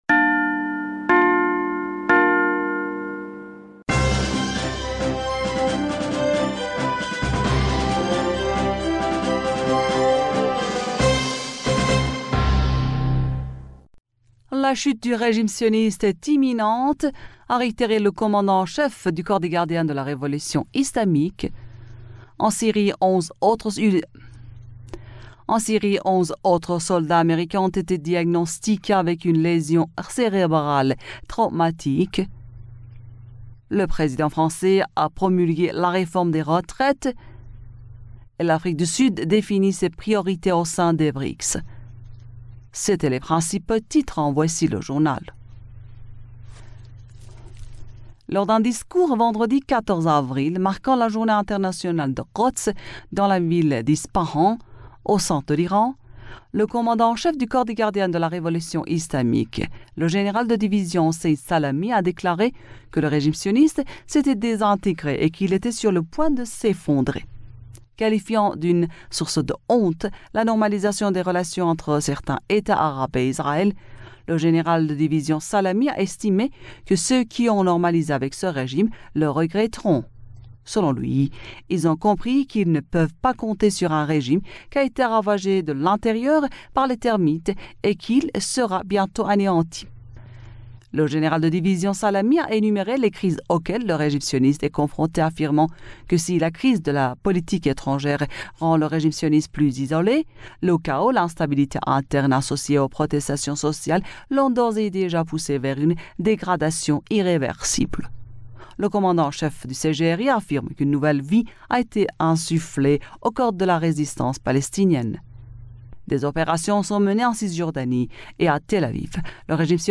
Bulletin d'information du 15 Avril 2023